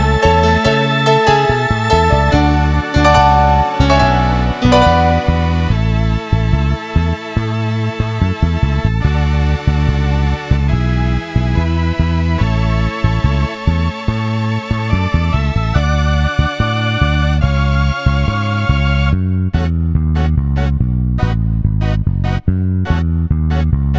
No Drums Or Backing Vocals Pop (1970s) 3:13 Buy £1.50